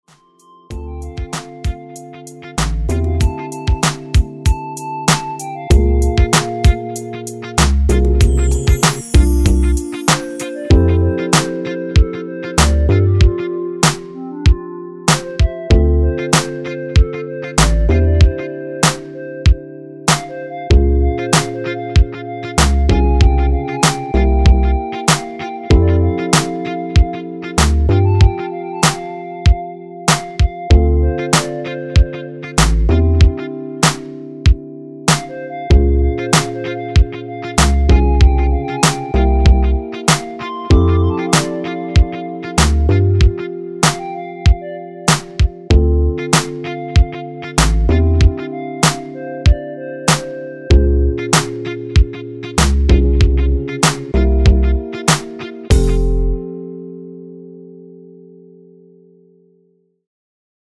엔딩이 페이드 아웃이라 라이브 하시기 좋게 엔딩을 만들어 놓았습니다.(미리듣기 참조)
원키에서(+3)올린 멜로디 포함된 MR입니다.
앞부분30초, 뒷부분30초씩 편집해서 올려 드리고 있습니다.